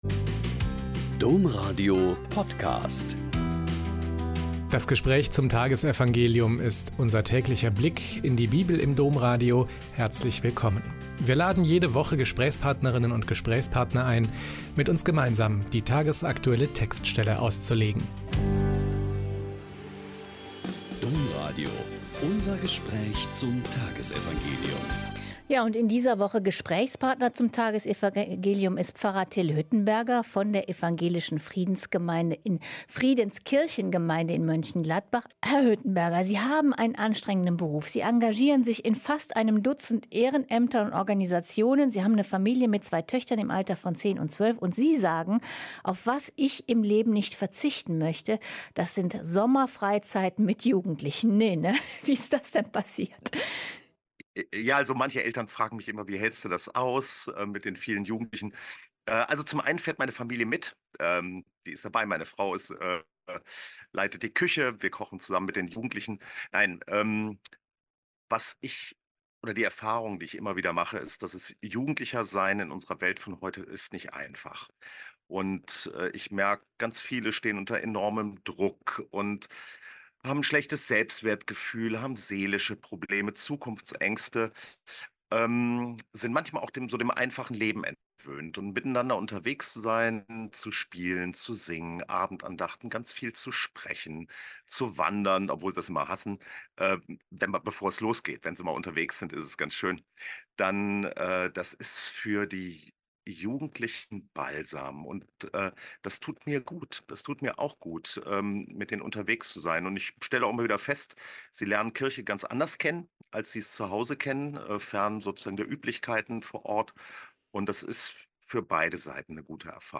Mk 2,1-12 - Gespräch